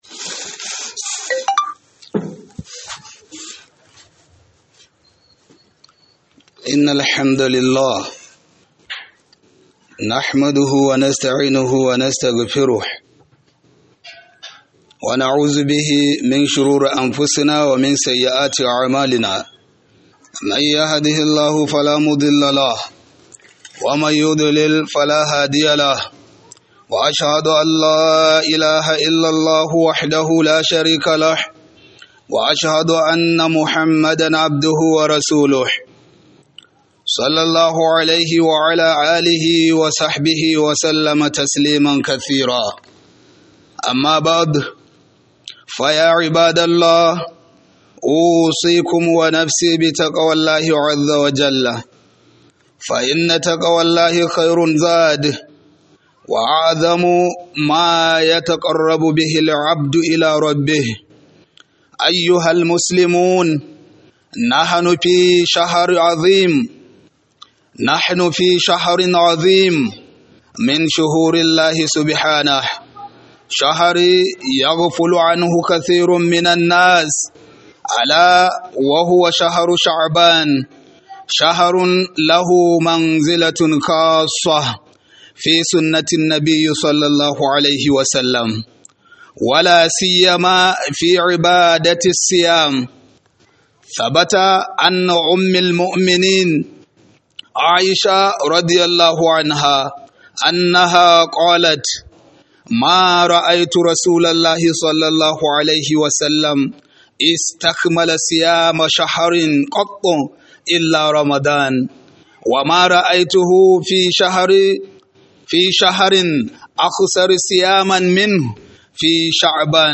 Khuduba - Falalar watan Ramadan